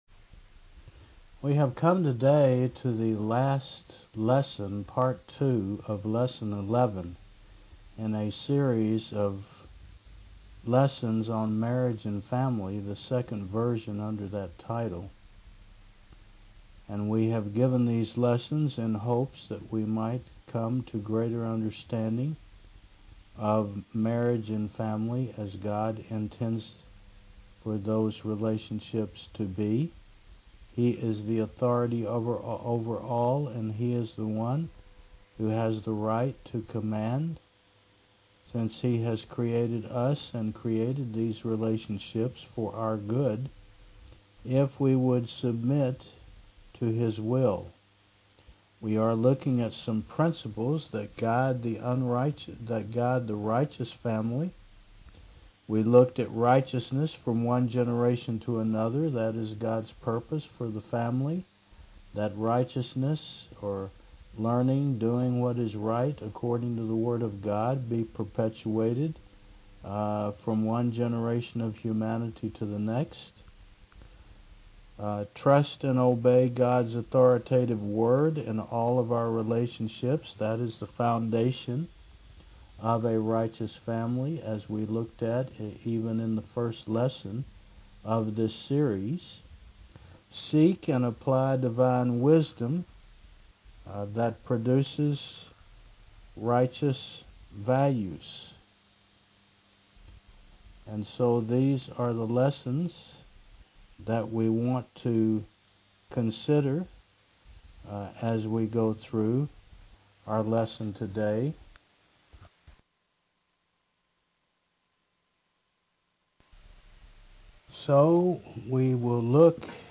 Service Type: Thu 10 AM